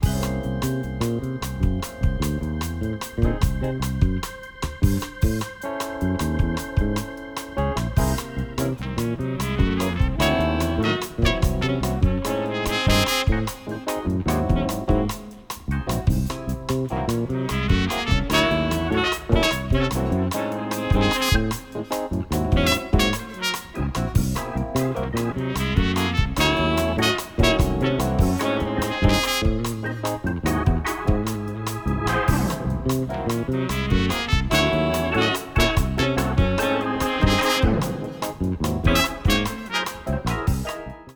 fretless bass
• Kurzweil PC88 (electric piano) with Phaser Moogerfooger
• Roland VK-7 (organ)
• Logic EVD6 (clavinet)
trumpet
tenor sax
electric bass
drums